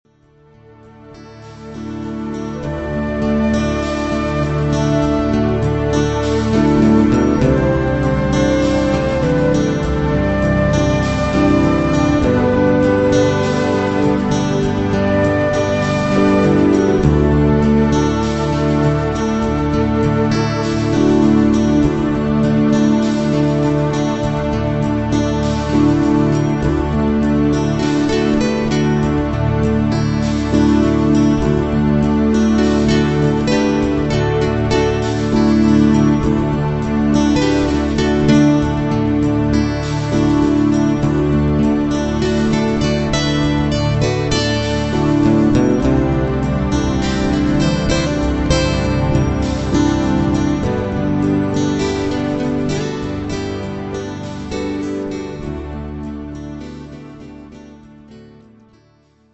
fiddle
bass
drums/perc.
guitar&percussion